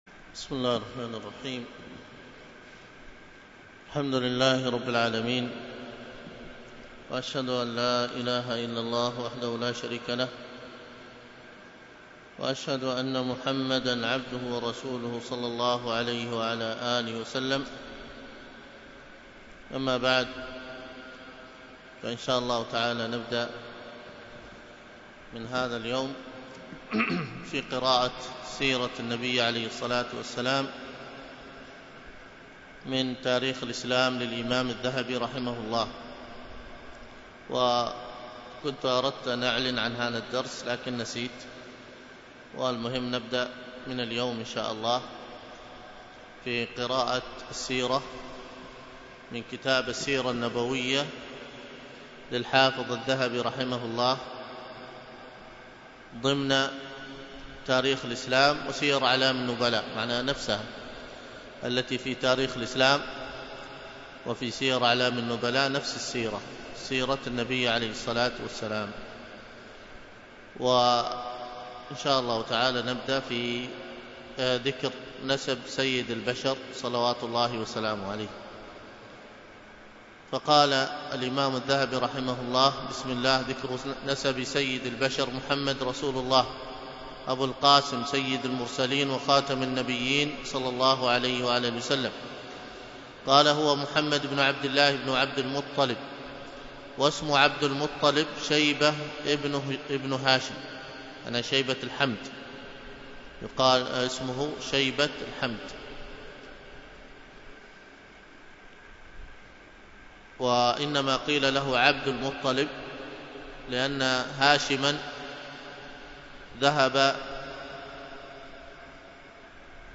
الدرس في التعليق على كتاب السيرة النبوية من تاريخ الإسلام للذهبي 2، ألقاها الشيخ